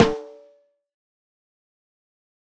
Snare Zion 8.wav